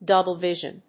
アクセントdóuble vísion